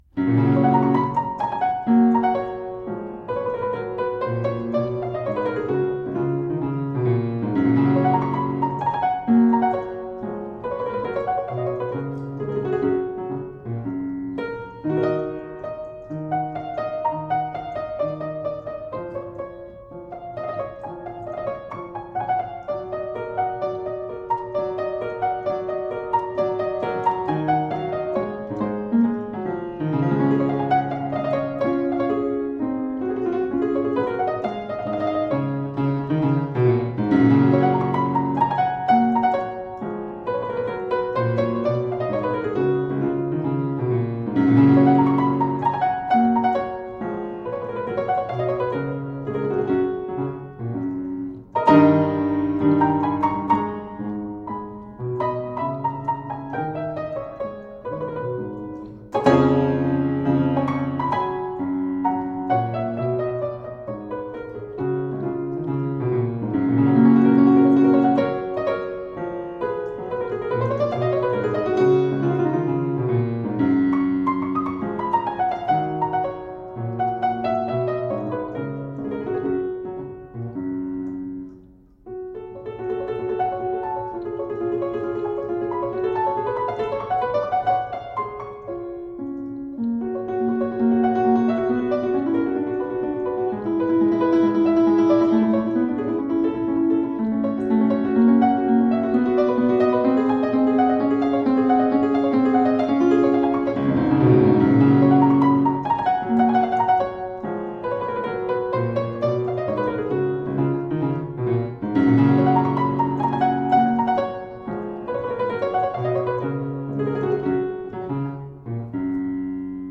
Fortepiano music from the early 1800s.